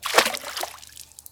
splash.wav